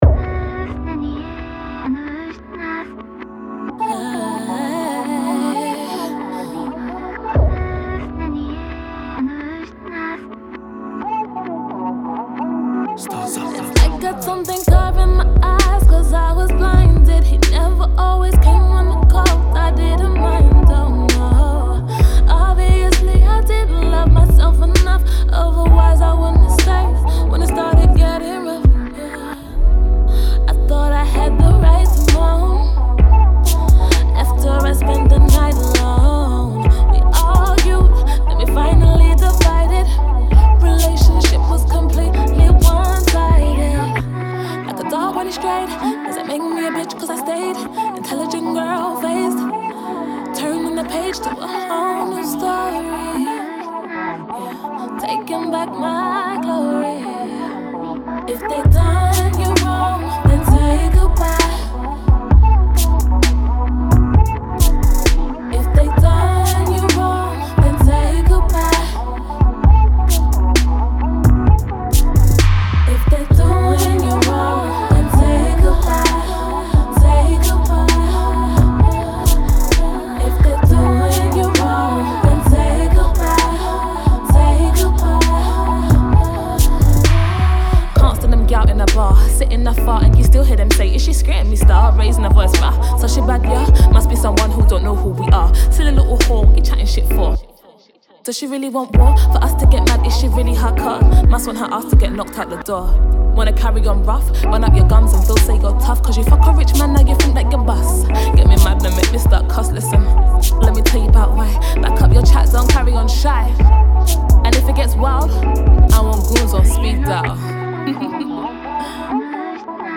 a slow smooth R&B Ballad
she dropped a Rap Verse as well